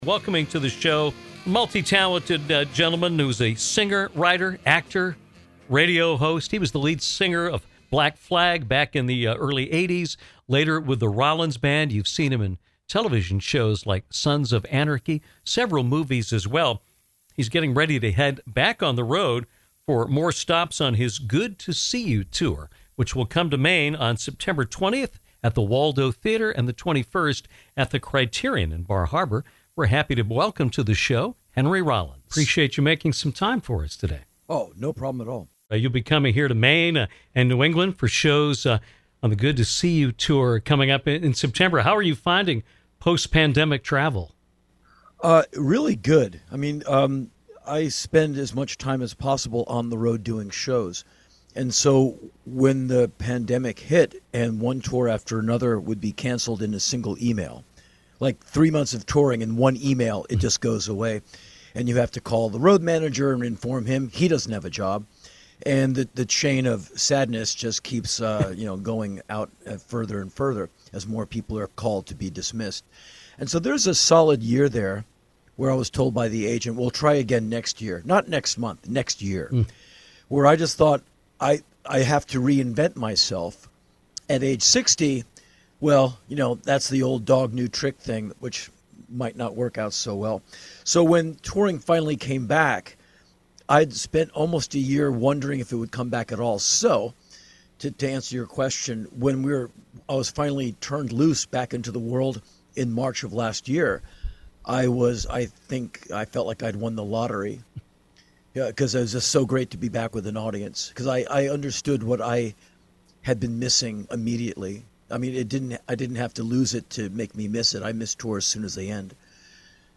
Henry Rollins joined us today on Downtown to discuss his GOOD TO SEE YOU Tour, which comes to Maine for two shows in September.